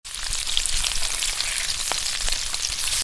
sizzle cooking Meme Sound Effect
sizzle cooking.mp3